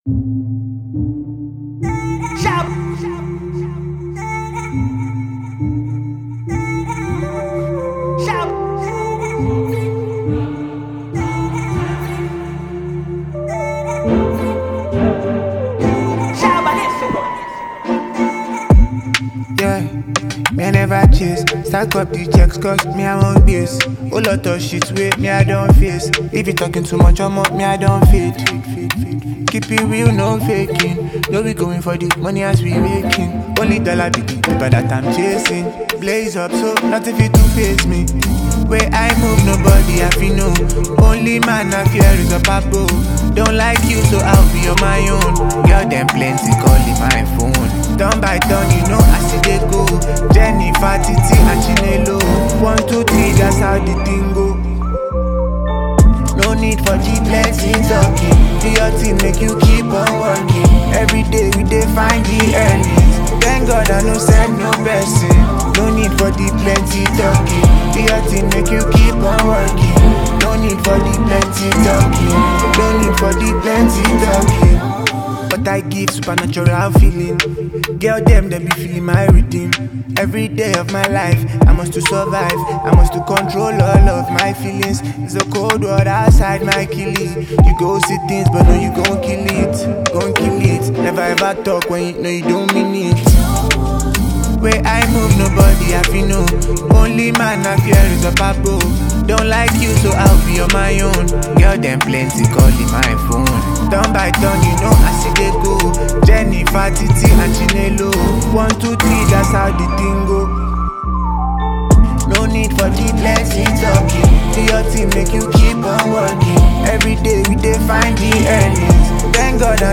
Country: Naija Music
enchanting melody